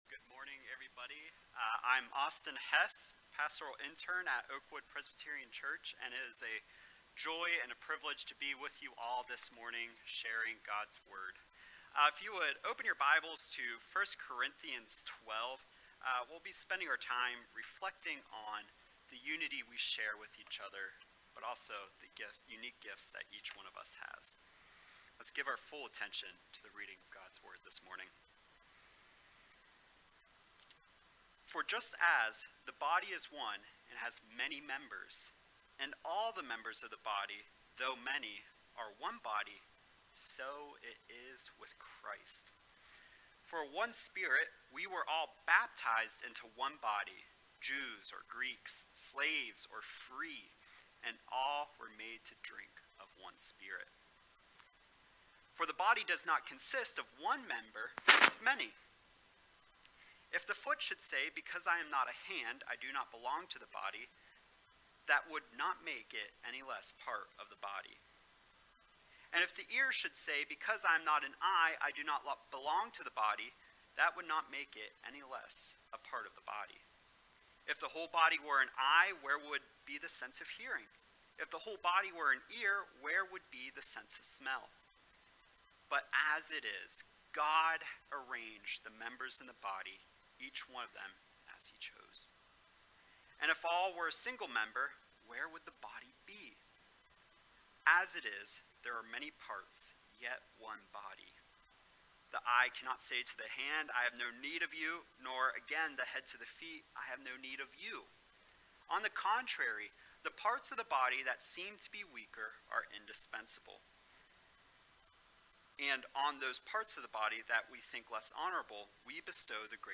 7.10.22-sermon-audio.mp3